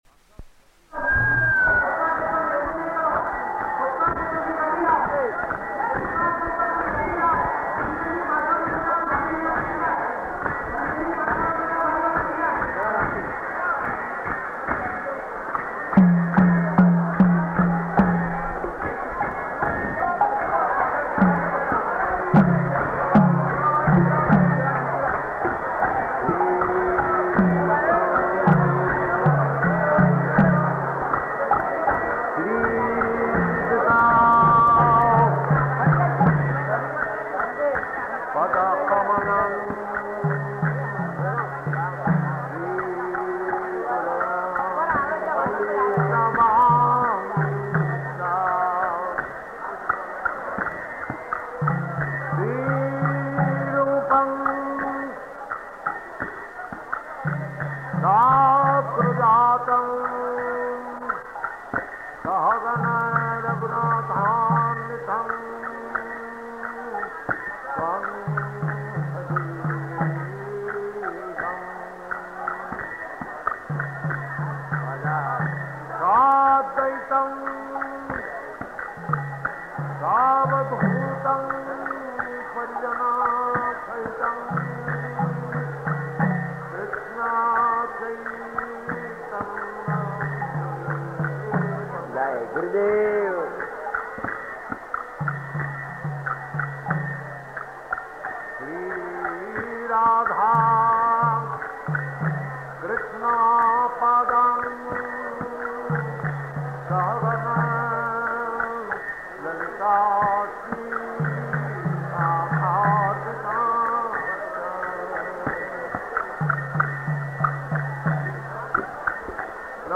Lecture in Hindi
Lecture in Hindi --:-- --:-- Type: Lectures and Addresses Dated: December 28th 1970 Location: Surat Audio file: 701228LE-SURAT.mp3 Prabhupāda: [leading kīrtana and delivers talk] [Hindi] Lecture Lecture in Hindi